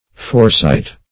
Foresight \Fore"sight`\, n.